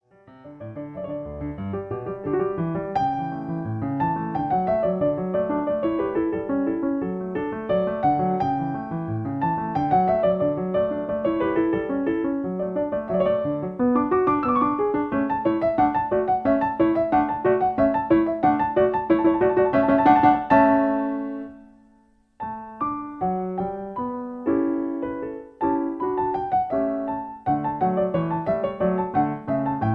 Original Key. Faster tempo. Piano Accompaniment